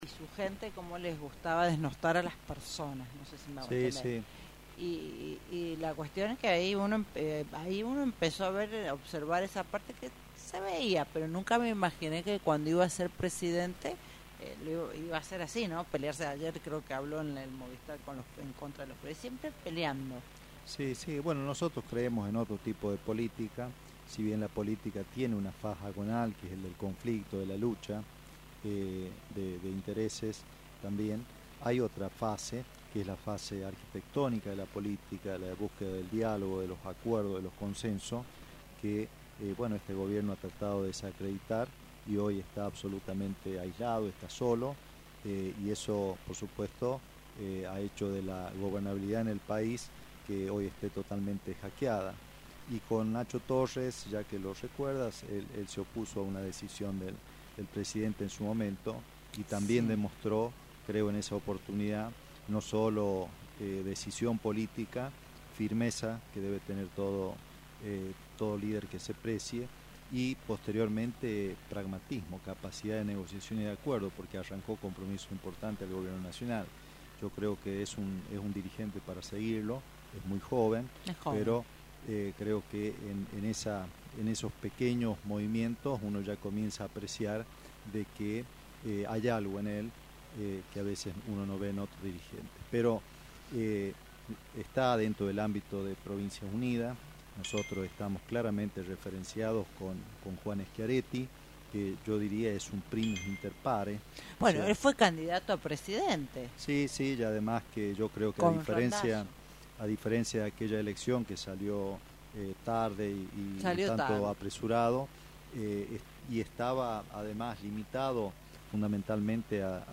El candidato a diputado nacional visitó los estudios de Radio Libertad y afirmó que el espacio se consolida como la única opción transformadora frente al agotamiento del kirchnerismo y la falta de sensibilidad del gobierno. Anticipó que el sector ya proyecta su armado político del 2027